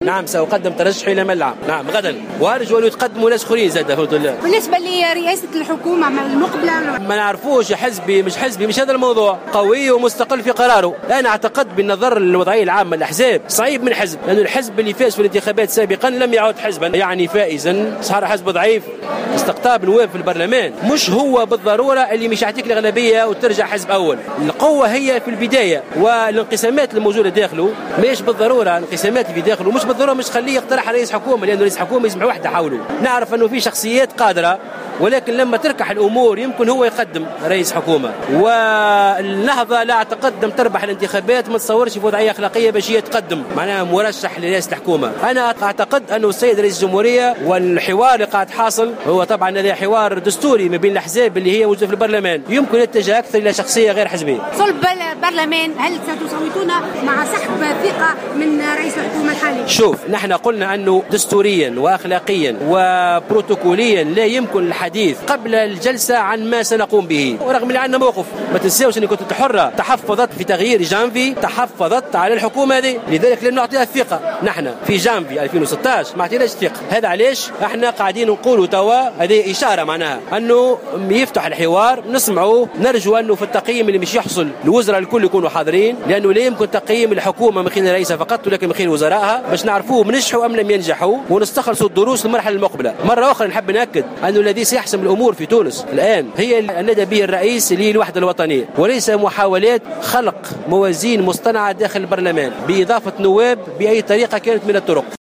وأضاف في تصريح لمراسلة "الجوهرة أف أم" أنه يدعو إلى تعيين رئيس حكومة "قوي ومستقل" بحسب تعبيره، مستبعدا أن يكون رئيس الحكومة الجديد ذي خلفية حزبية.